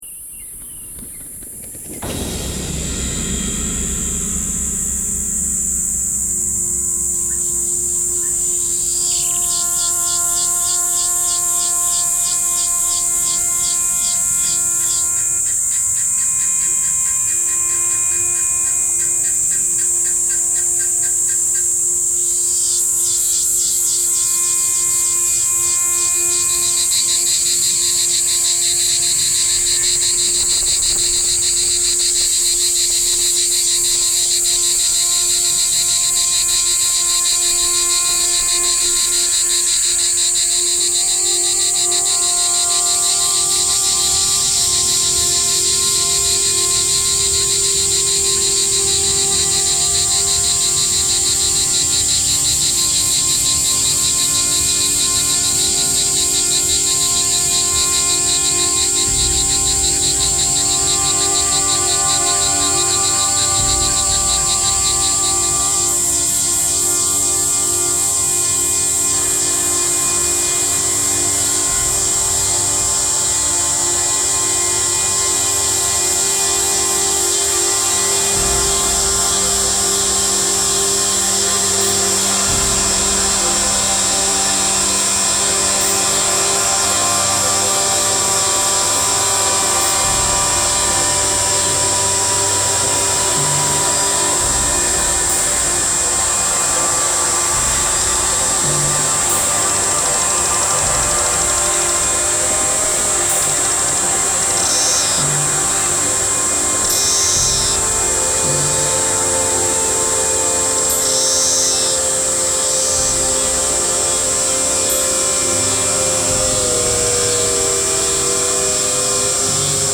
Sound Art Series